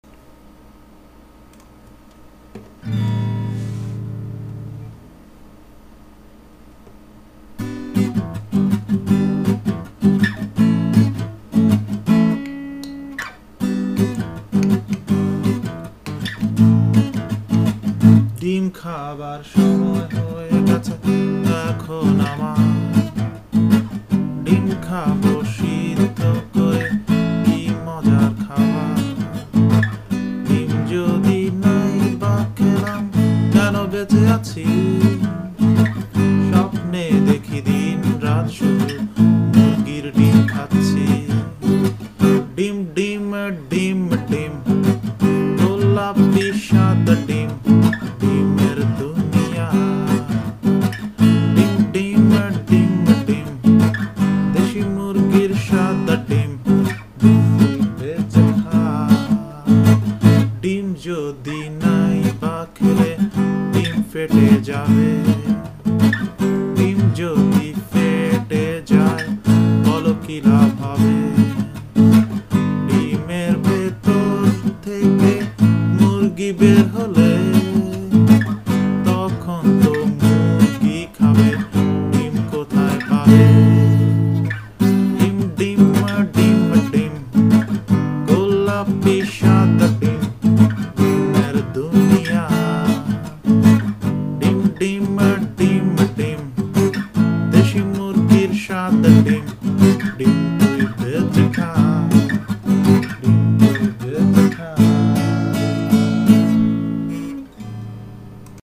The second one is just g1bBeRi$h. I was playing my guitar and was singing ‘ja ichha tai’.